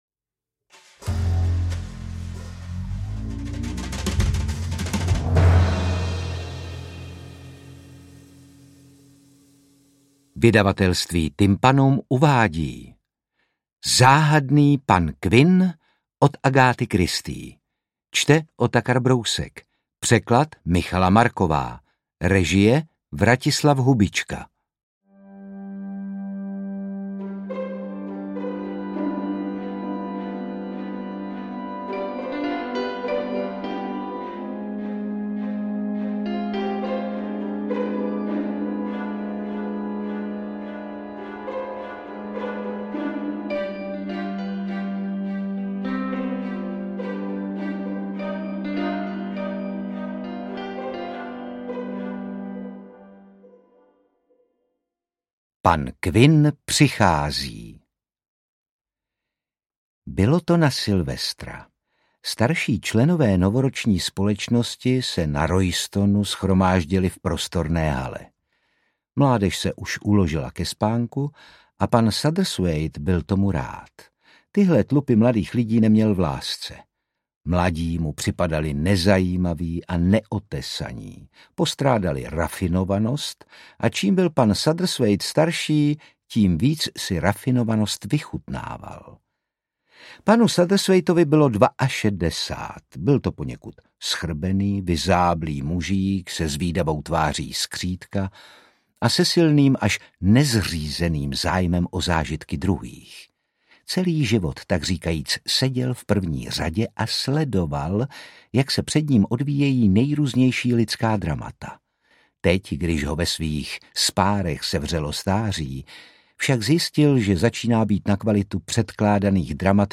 Interpret:  Otakar Brousek